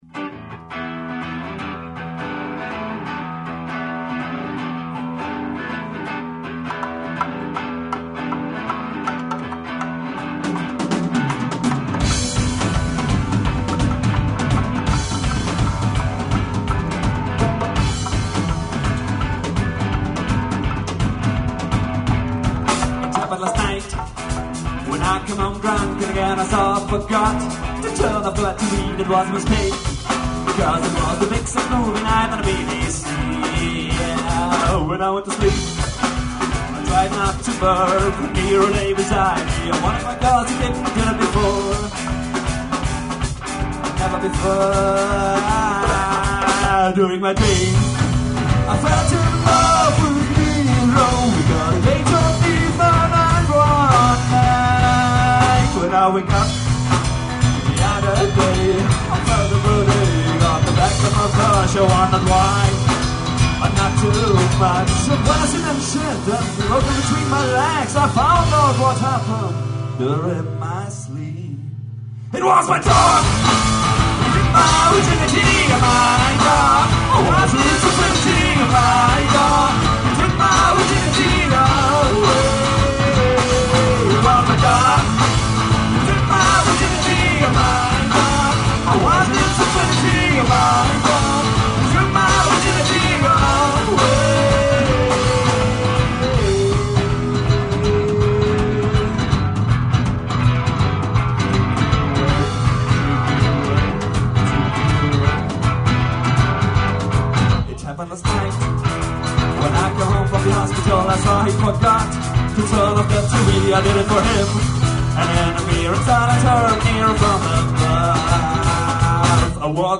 Cafe Stockwerk, Graz 18.04.01